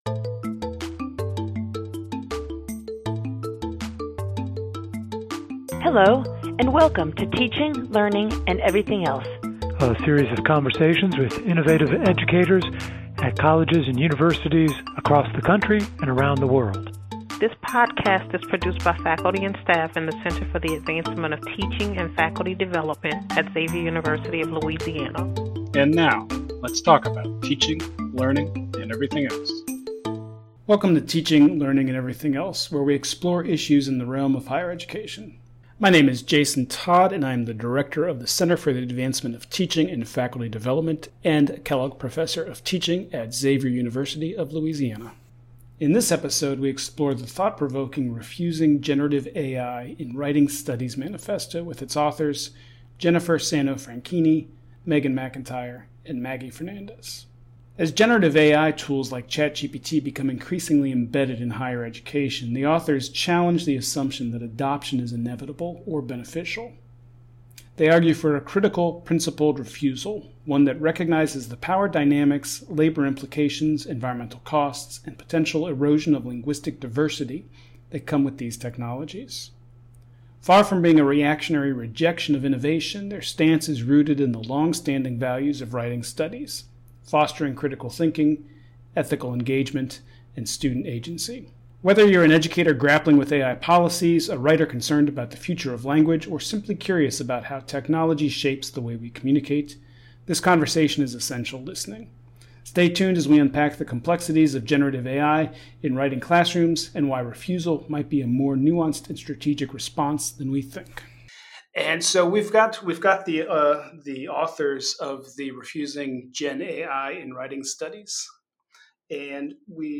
Each episode consists of a conversation with a teacher in higher education.